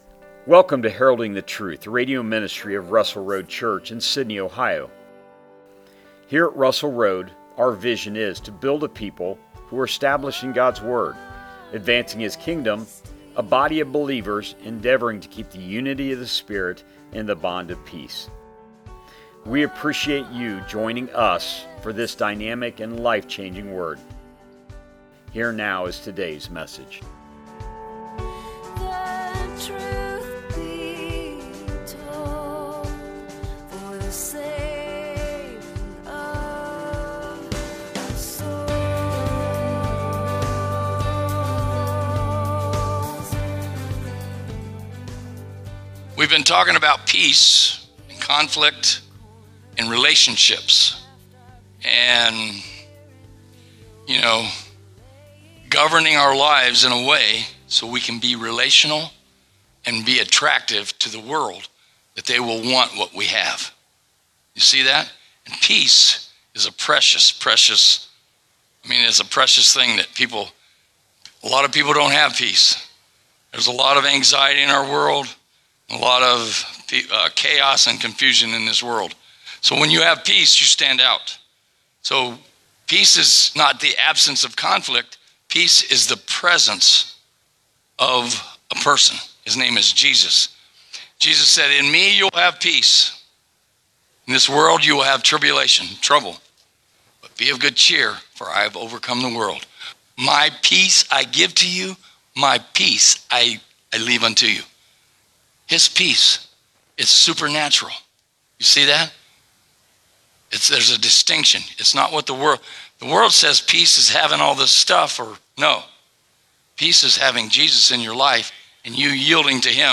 Sermons | Russell Road Church